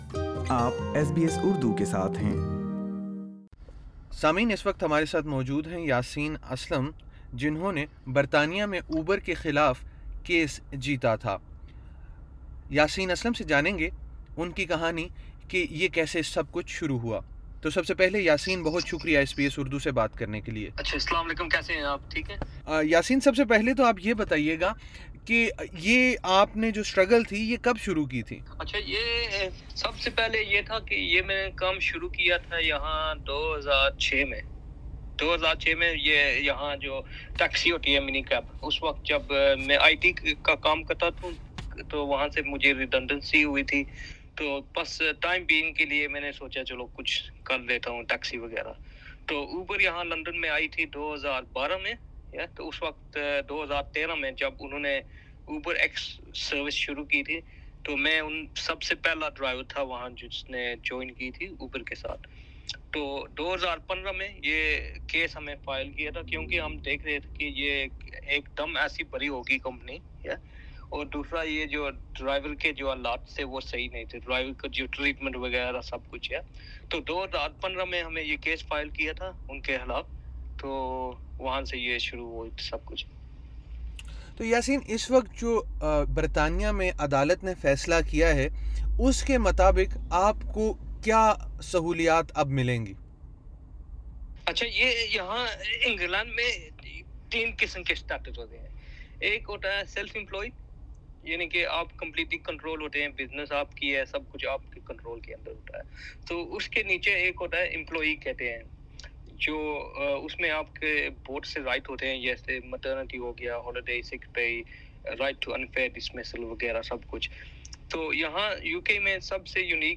In a five-year legal battle with rideshare giant, Uber, two drivers of Pakistani origin succeeded in convincing the court to allow working rights and vacation pay for workers like them. SBS Urdu spoke with the major stakeholders of the UK case to find out what implications it could have for rideshare drivers in Australia.